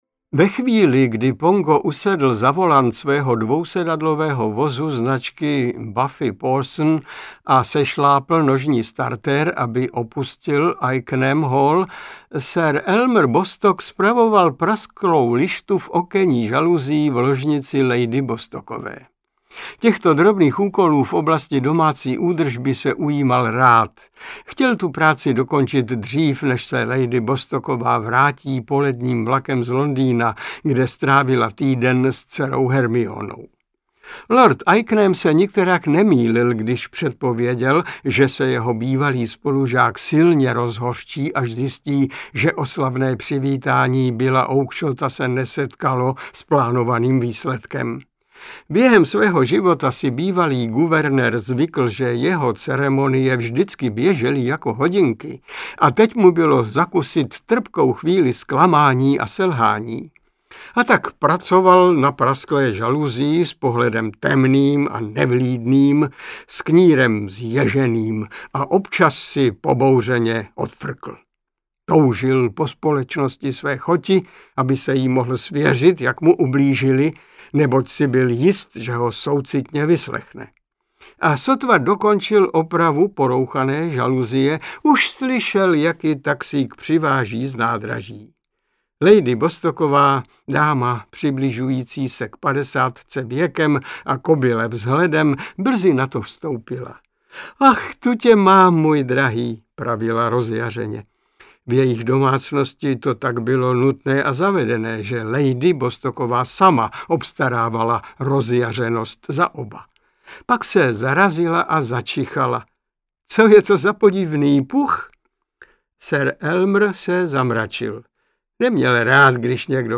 Strýc Dynamit audiokniha
Ukázka z knihy
• InterpretMiloň Čepelka